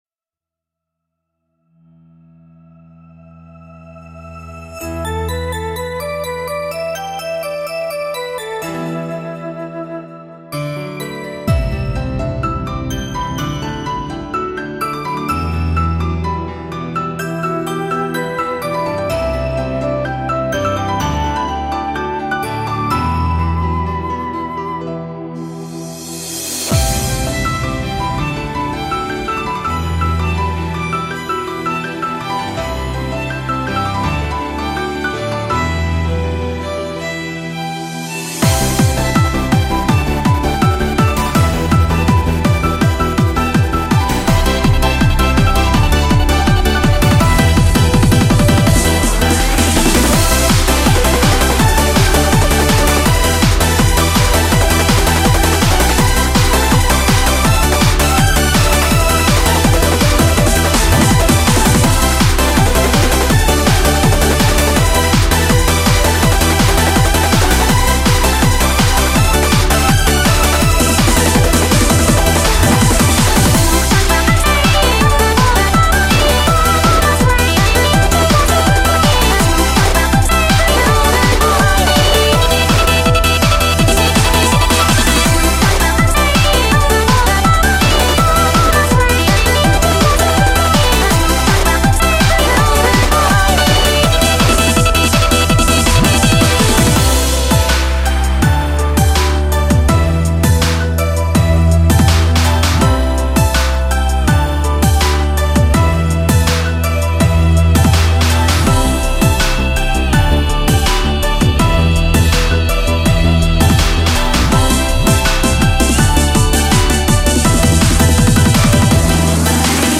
Hah, the intro is so silly~